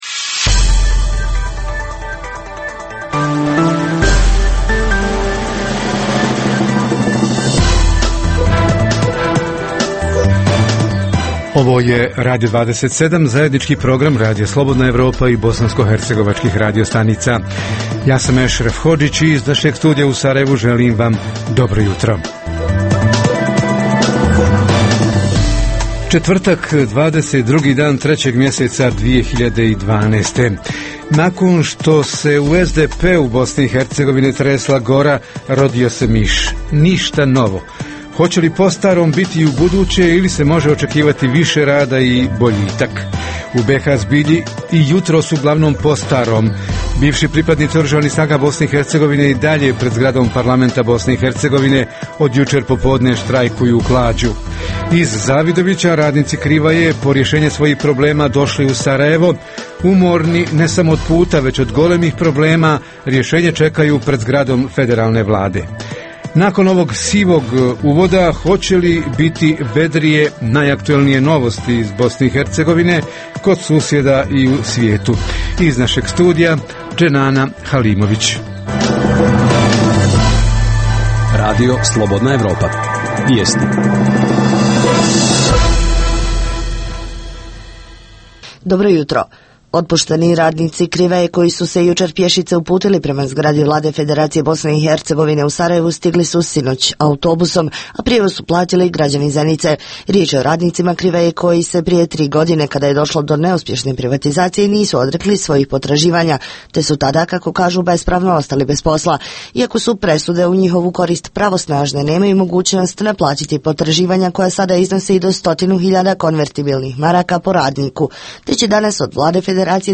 Hoće li biti umanjenja plaća uposlenicima u lokalnoj administarciji, u javnim preduzećima i ustanovama? Reporteri iz cijele BiH javljaju o najaktuelnijim događajima u njihovim sredinama.
Redovni sadržaji jutarnjeg programa za BiH su i vijesti i muzika.